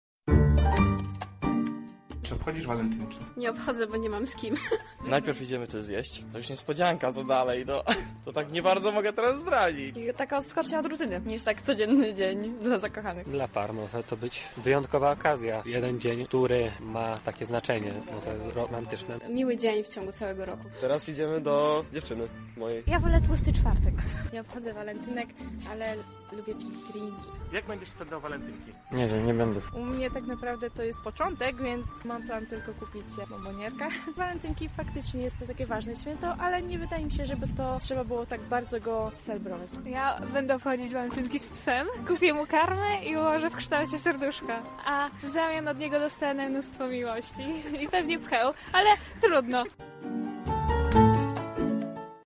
Zapytaliśmy mieszkańców Lublina co myślą o Dniu Zakochanych, posłuchajcie: